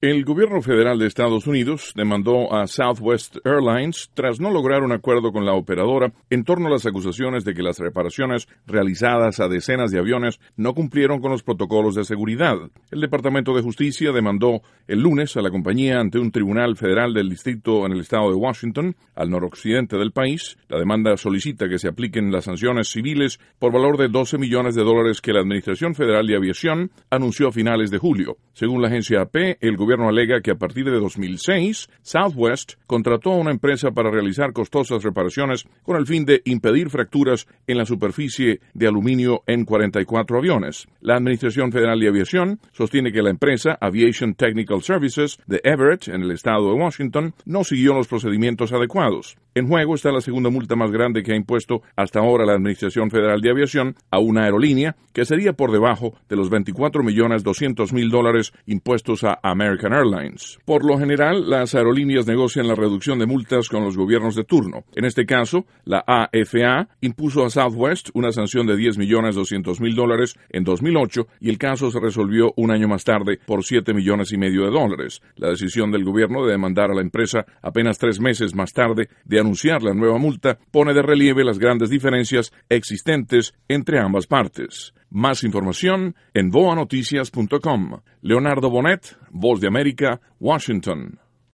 desde la Voz de América, en Washington.